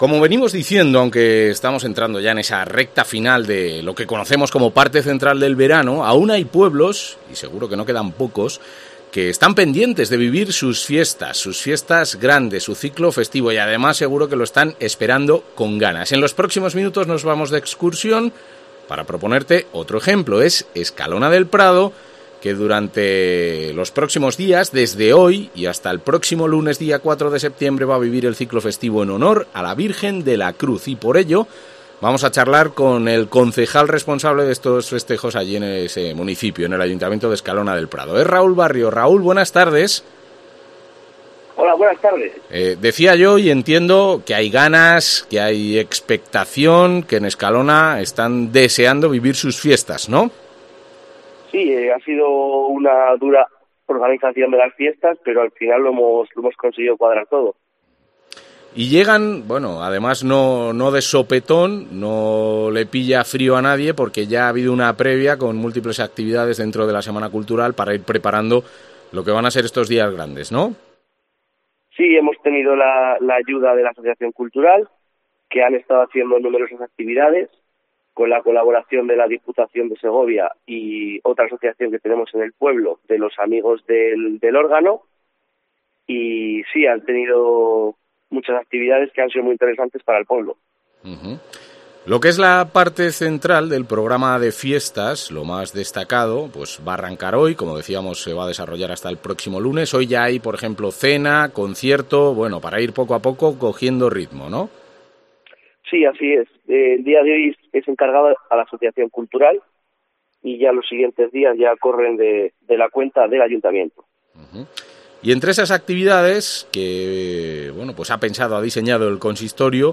Raúl Barrio, concejal del Ayuntamiento de Escalona del Prado